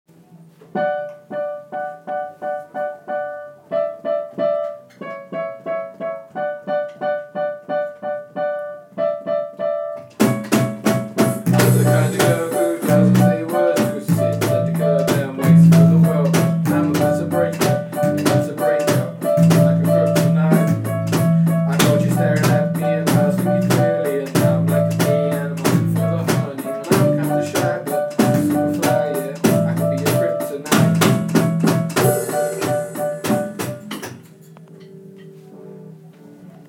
Piano
Drums
Bass Guitar